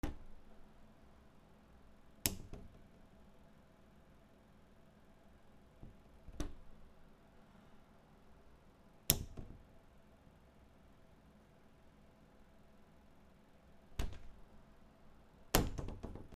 簡単な扉 磁石